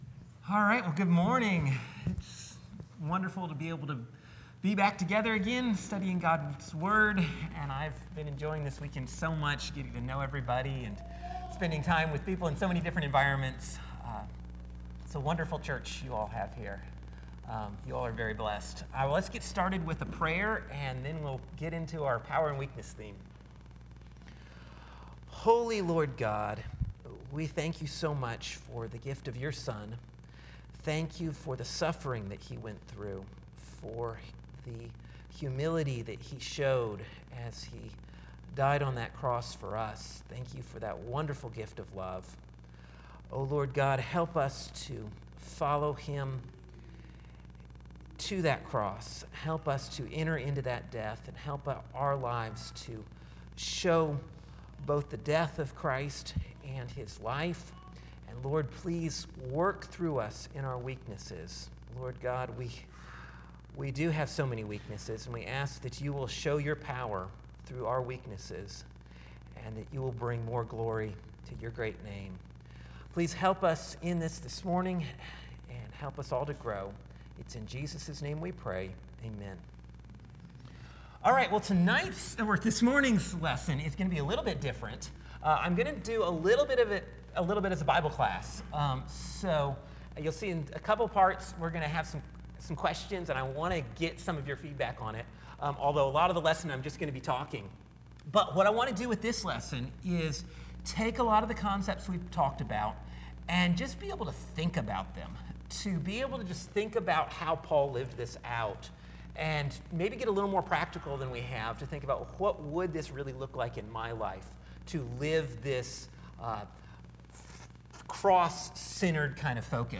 Sorrow and Rejoicing – 2 Cor. 6 (Bible Class)